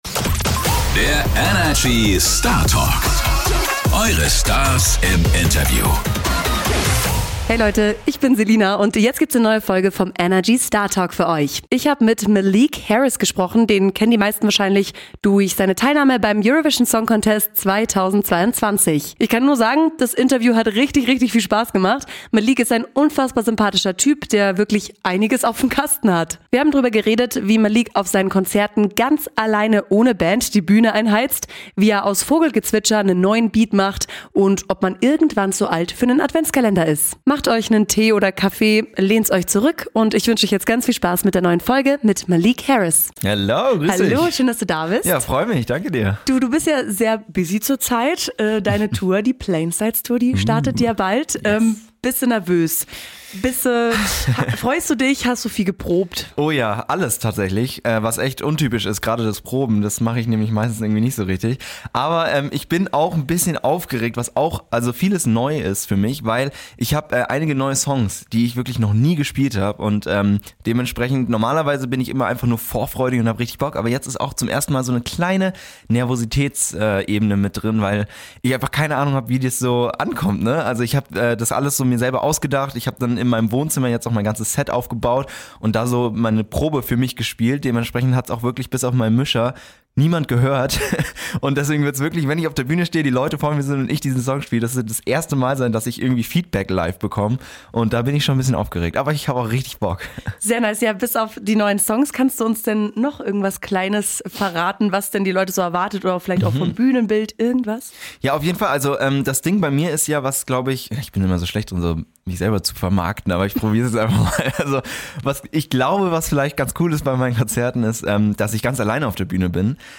Freut euch auf super lockeres, sympathisches und herzerwärmendes Gespräch mit Malik Harris!